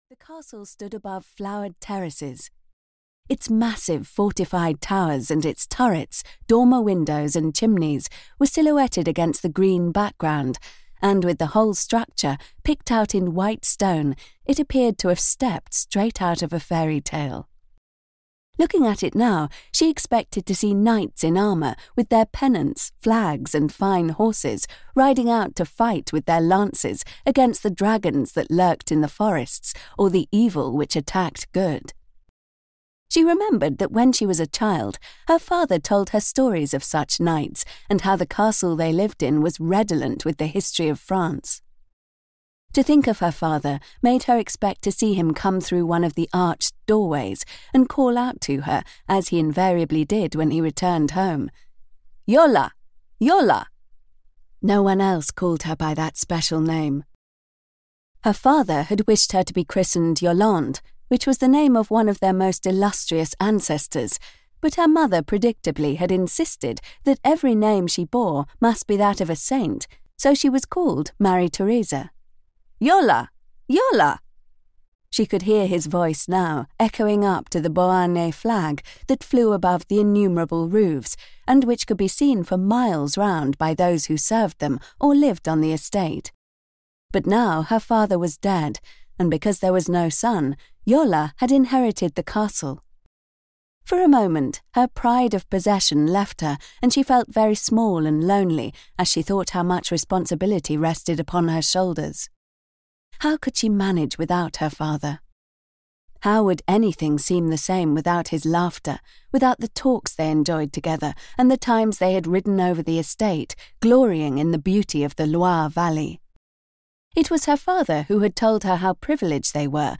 Audiobook The Castle Made for Love written by Barbara Cartland. Yola has the perfect life as a countess– blessed with grace, wealth and intellect.
Ukázka z knihy